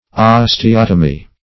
Search Result for " osteotomy" : Wordnet 3.0 NOUN (1) 1. surgical sectioning of bone ; The Collaborative International Dictionary of English v.0.48: Osteotomy \Os`te*ot"o*my\, n. 1.